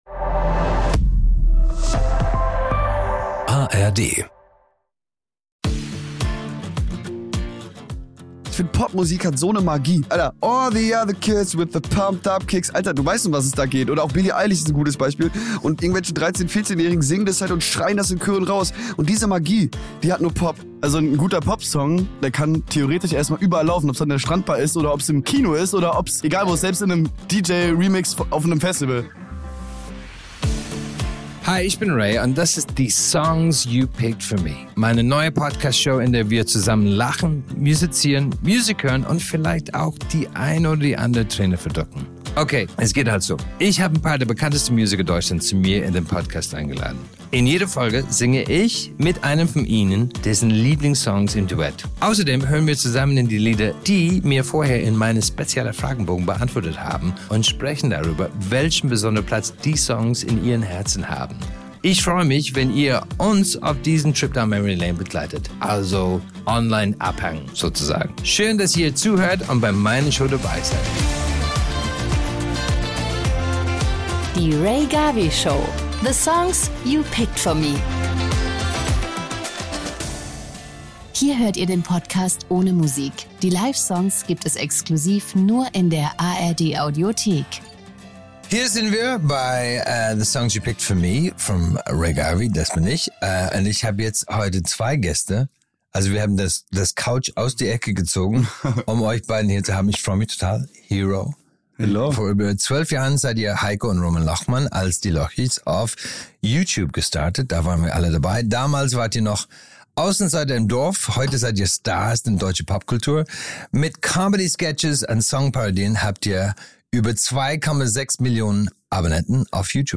In dieser Folge begrüßt Rea Garvey die Zwillinge Heiko und Roman Lochmann mit ihrer Band HE/RO.
Sie erzählen von der wilden Zeit des Erwachsenwerdens, von Herzschmerz und von den kleinen und großen Hürden, die sie als Brüder und Künstler bisher schon meistern mussten. Natürlich kommt die Musik auch nicht zu kurz: Es gibt wieder zwei exklusive Performances, die es nur in der ARD Audiothek zu hören gibt.